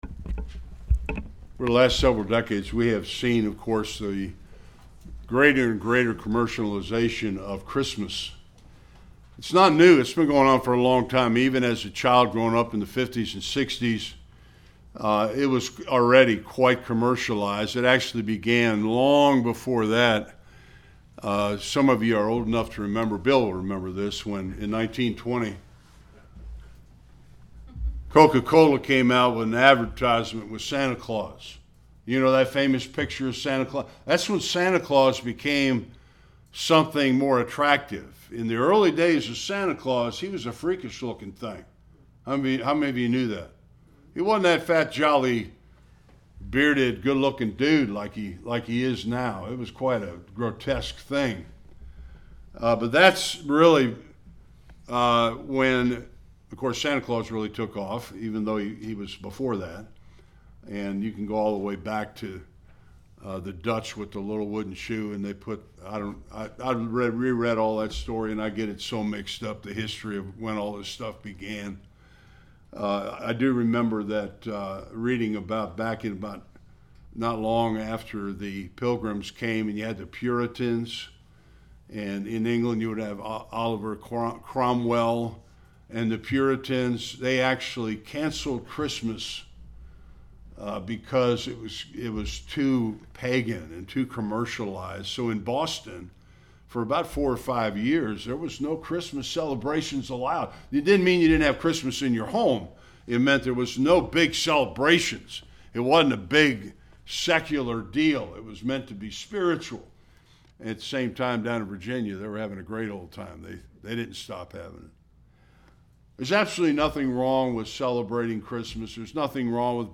Various Passages Service Type: Sunday Worship Much of the real significance of Christmas is seldom talked about.